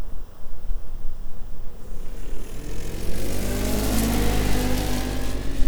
Snowmobile Description Form (PDF)
Subjective Noise Event Audio File - Run 1 (WAV)